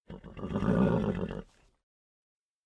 horse-sound